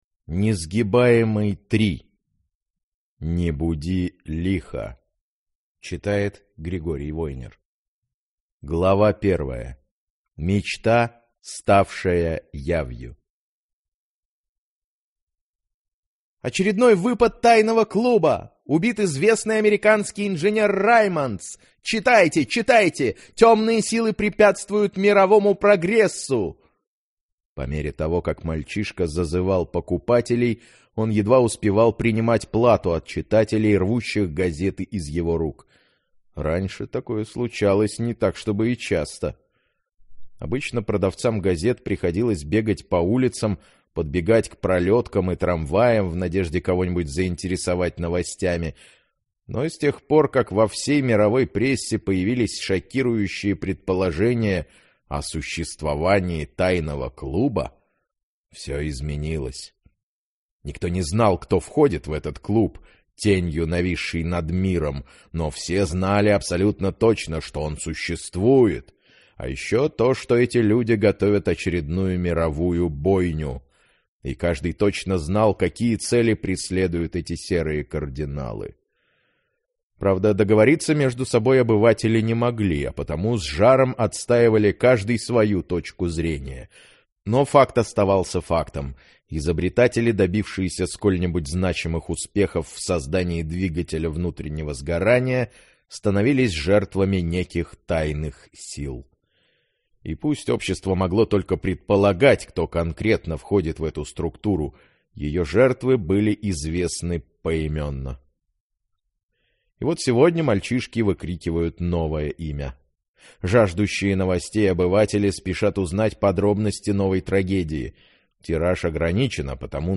Аудиокнига Несгибаемый. Не буди лихо…